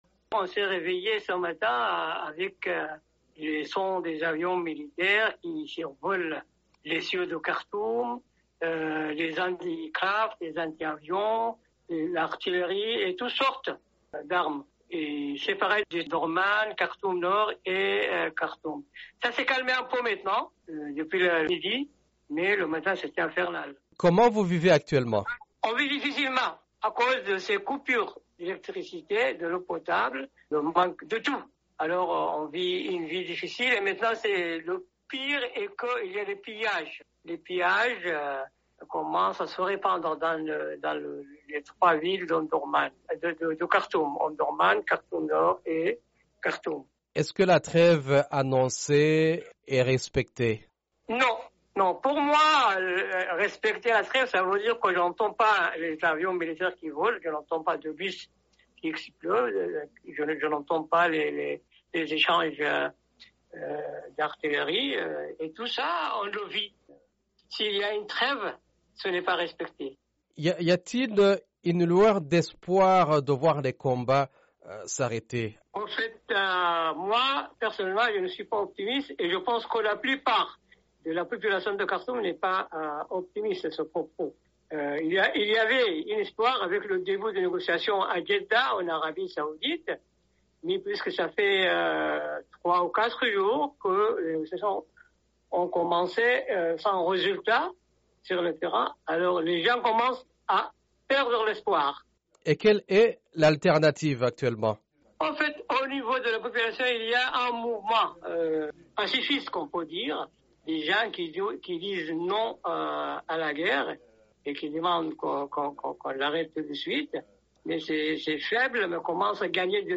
Témoignage d'un habitant de Khartoum: "les gens commencent à perdre l'espoir"